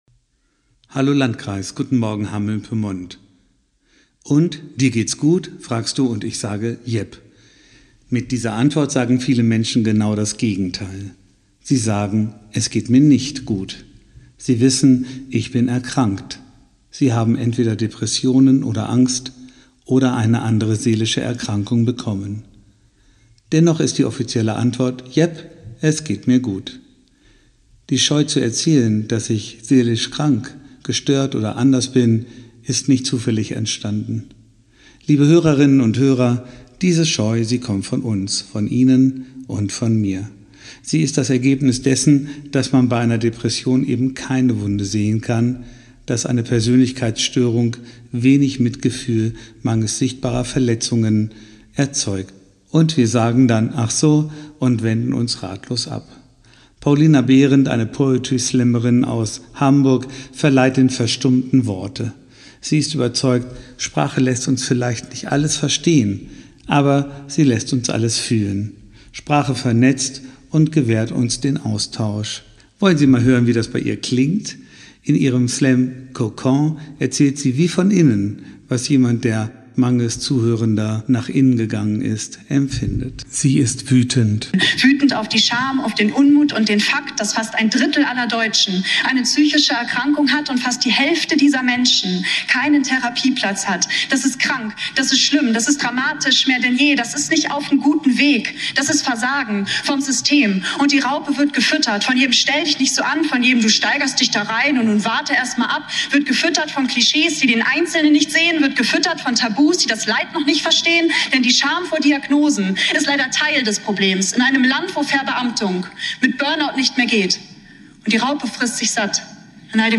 Radioandacht vom 30. Juni